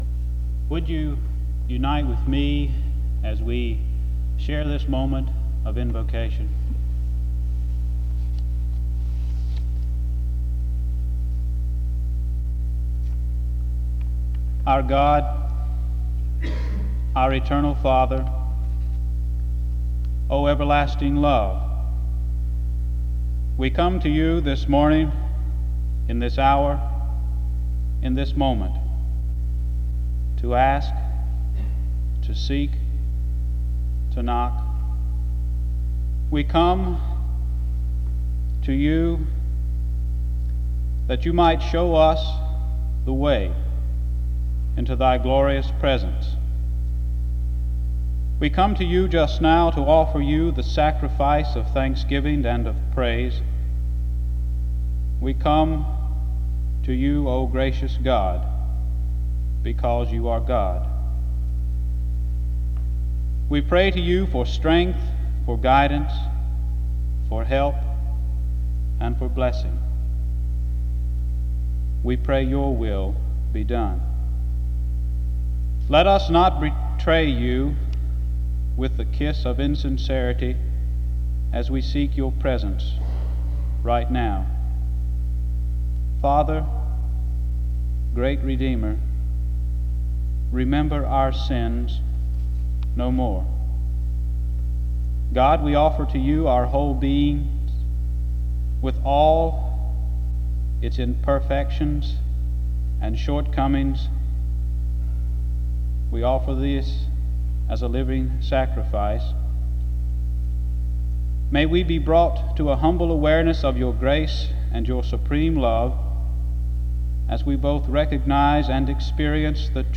SEBTS Chapel - Student Coordinating Council February 26, 1975
This service was organized by the Student Coordinating Council.